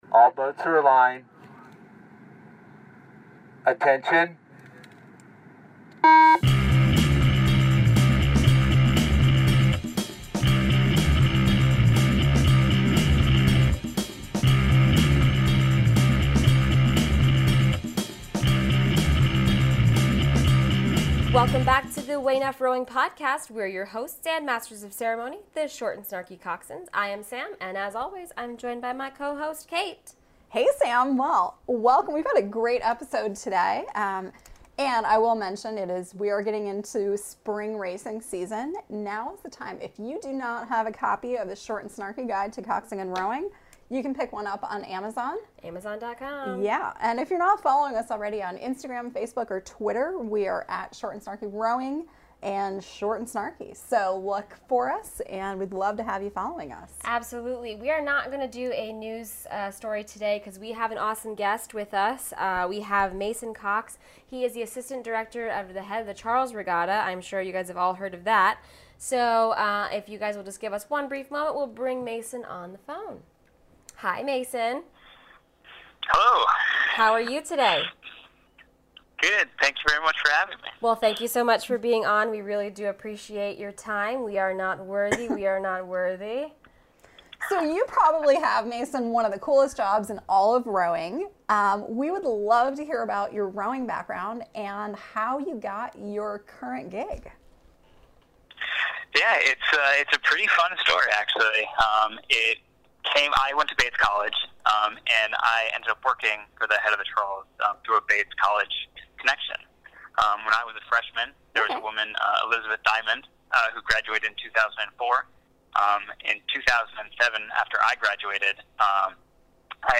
We interview